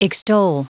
Prononciation du mot extol en anglais (fichier audio)
Prononciation du mot : extol